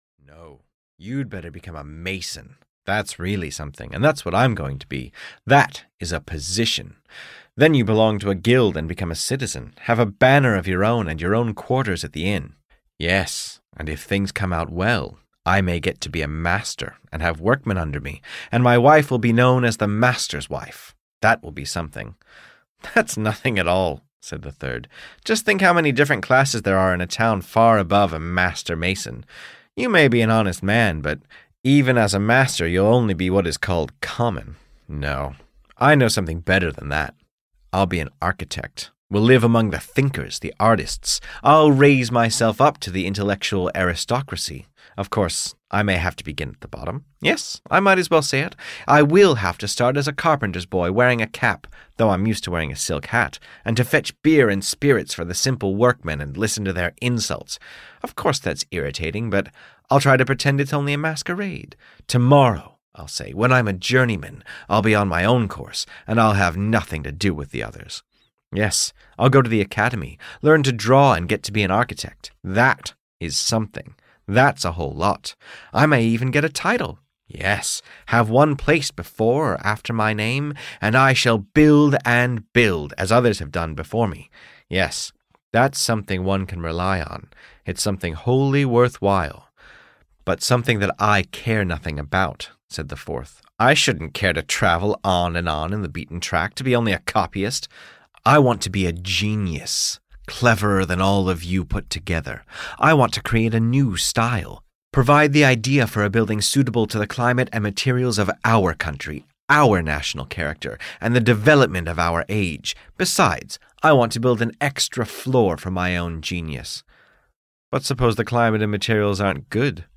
Something (EN) audiokniha
Ukázka z knihy